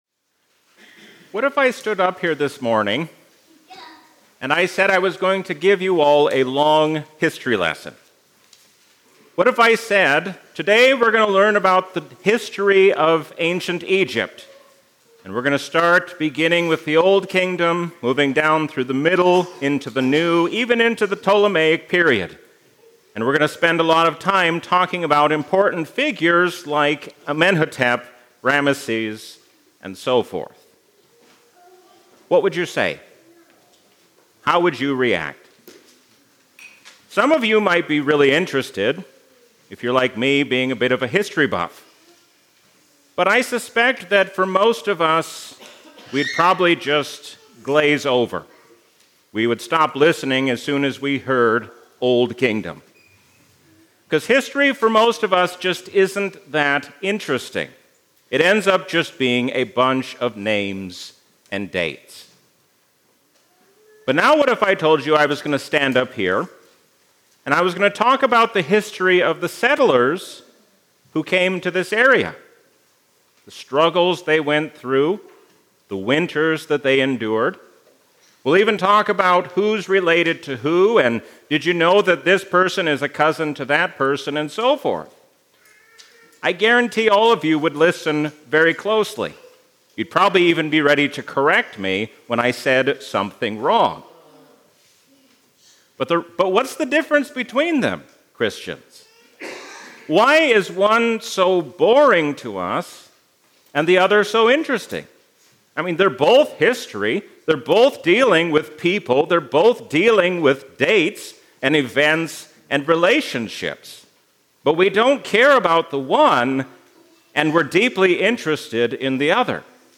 A sermon from the season "Easter 2025."